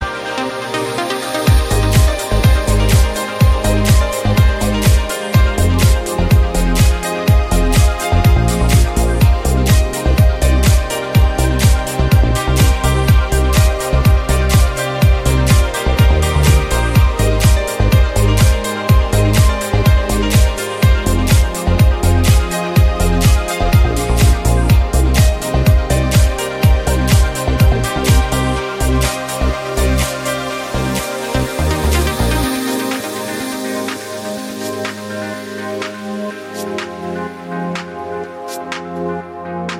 • Качество: 128, Stereo
красивые
deep house
мелодичные
без слов